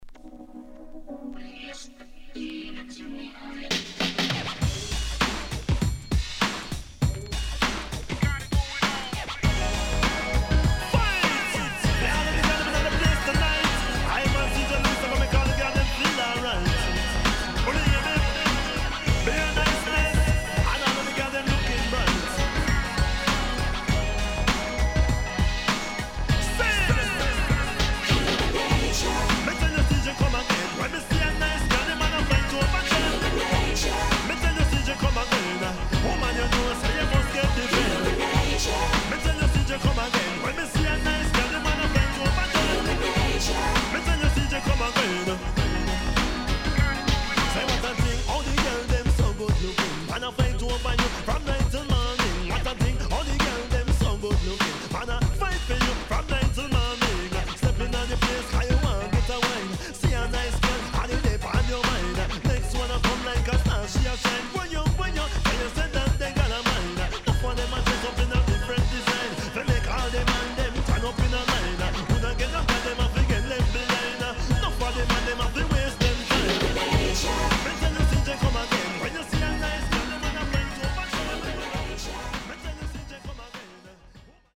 Nice Deejay.Original JKT.Good Condition
SIDE A:少しチリノイズ入りますが良好です。